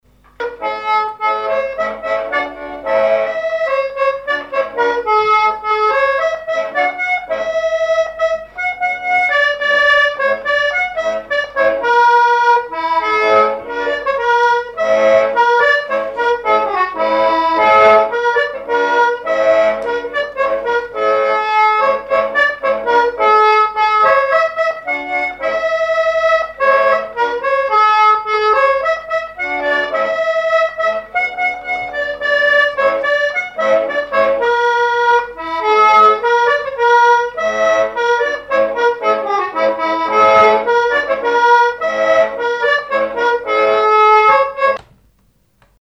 Fonction d'après l'analyste gestuel : à marcher
Genre laisse
accordéoniste
Pièce musicale inédite